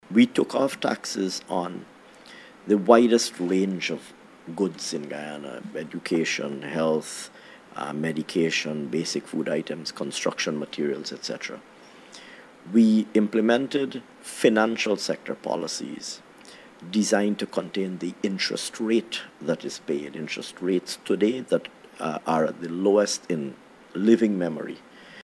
In an interview on the Starting Point Podcast, the Minister noted that Guyana, like the rest of the world, is affected by global price increases, but also reflected on the measures that were rolled out by the government.